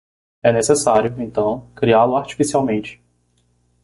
Pronunciado como (IPA)
/aʁ.t͡ʃi.fi.siˌawˈmẽ.t͡ʃi/